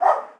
dog_bark_small_09.wav